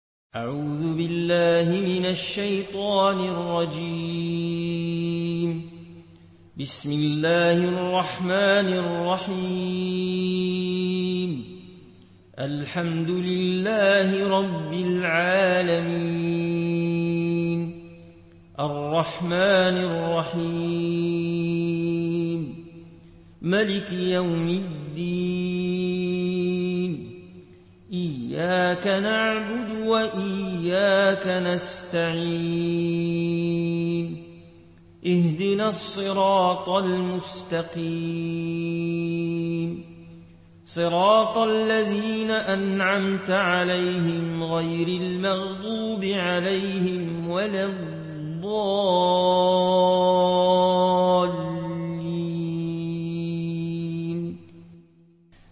Riwayat Warch